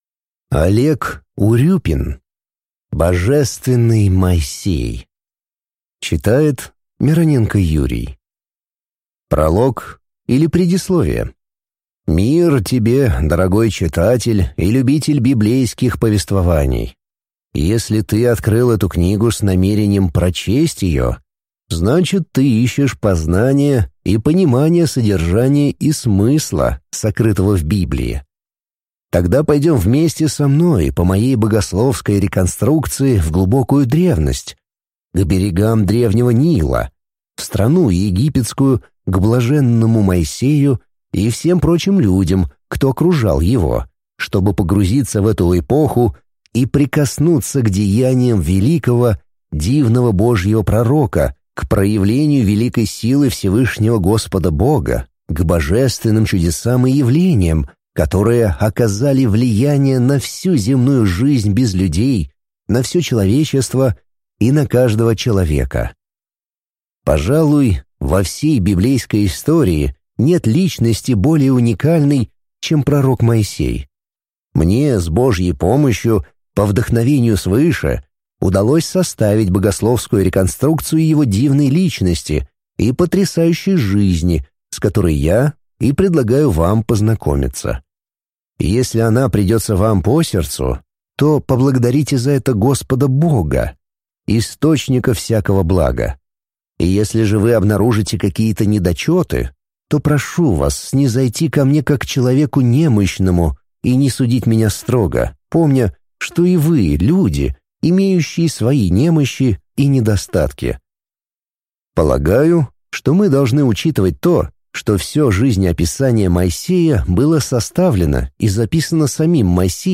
Аудиокнига Божественный Моисей | Библиотека аудиокниг